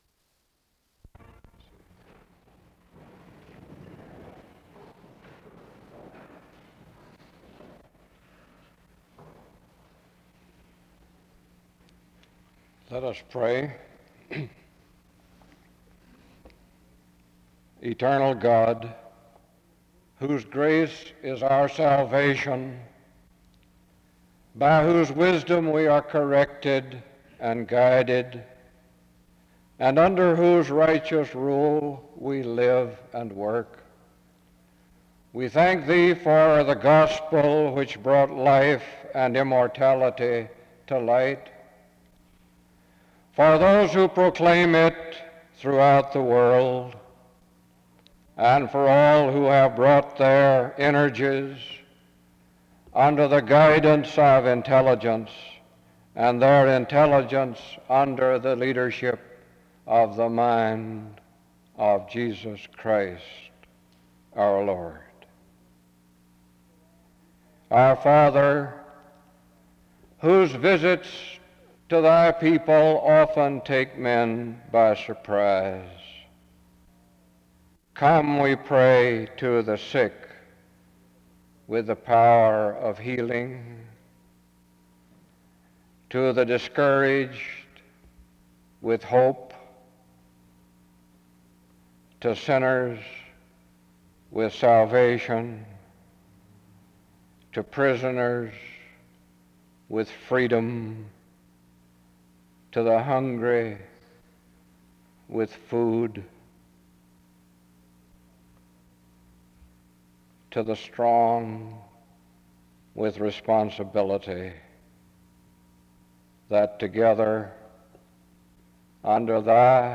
SEBTS Chapel
He preached about what it means to be a missionary and to be in ministry.